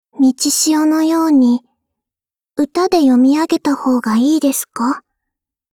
[[Category:碧蓝航线:朝潮语音]]